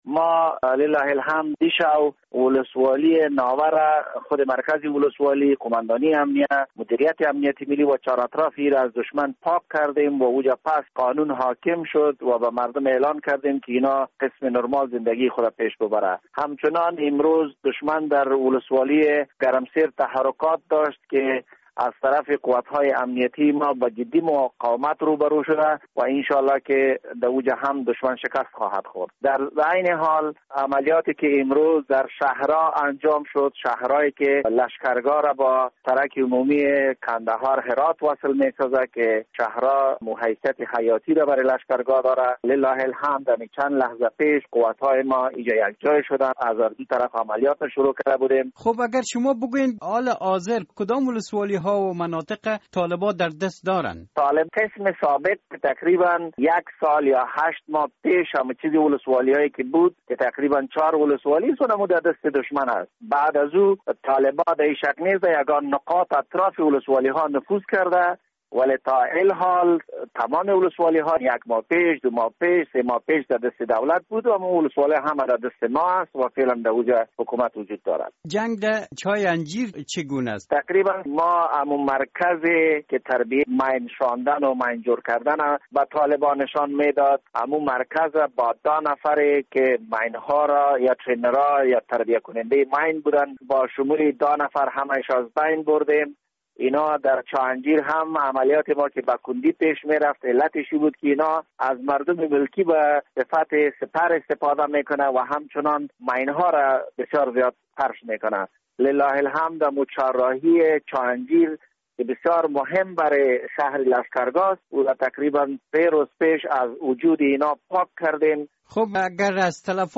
مصاحبه - صدا
حیات الله حیات والی هلمند